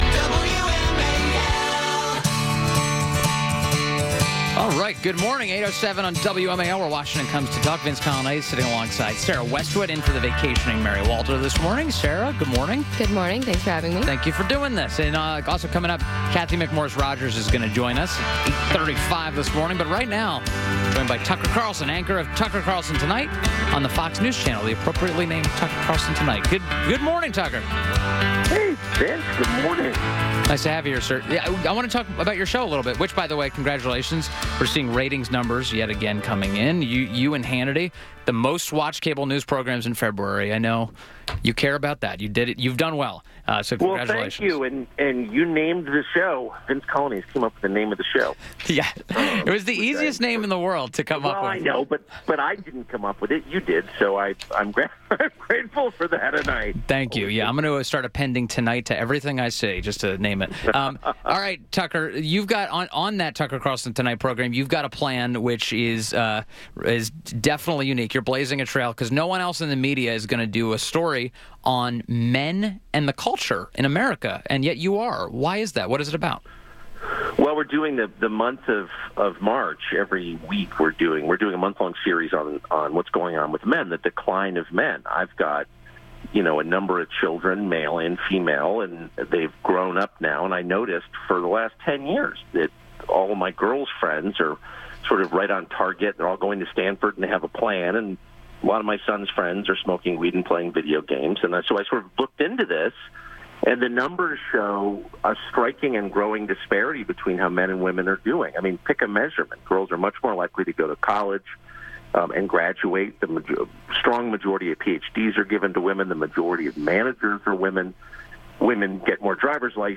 WMAL Interview - TUCKER CARLSON - 02.28.18